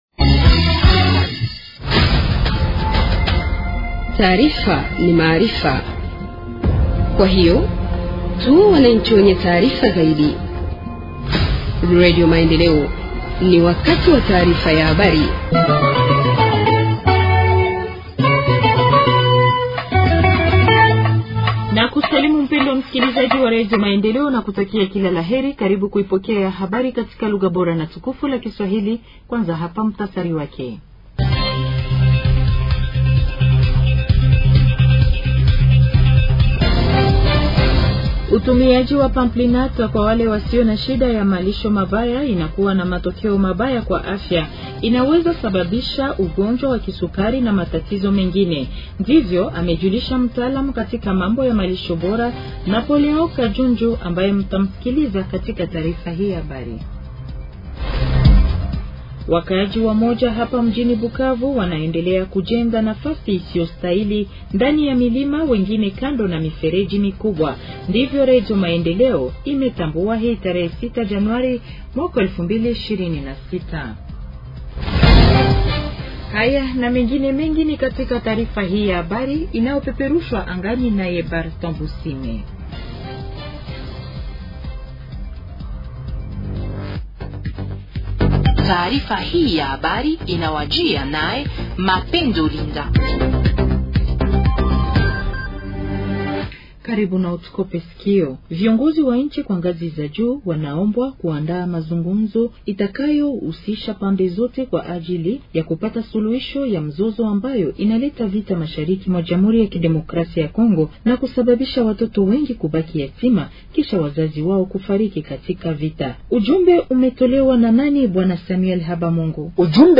Journal en swahili du 07 janvier 2026 – Radio Maendeleo